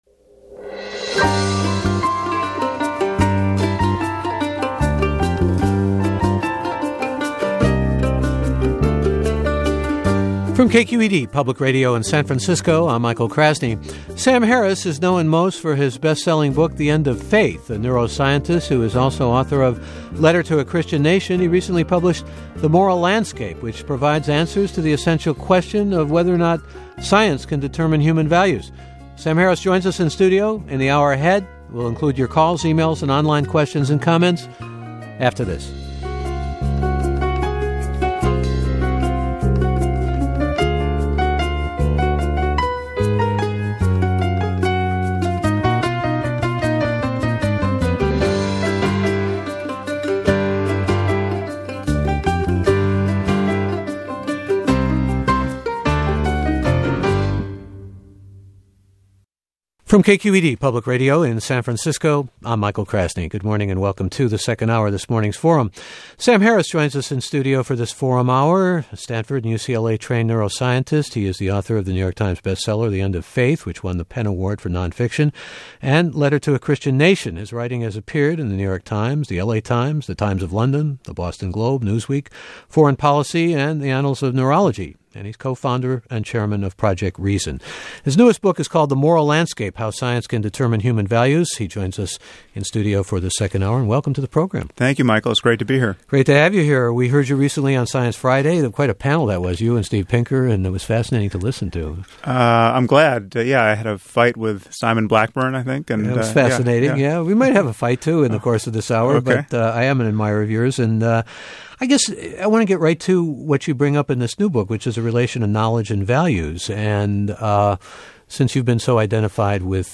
Sam on KQED radio He discusses The Moral Landscape: How Science Can Determine Human Values. 11/10/10 Interview by right wing conservative Michael Medved Interviews with Sam and Richard Dawkins on NPR: Keeping Religion Out of Public Policy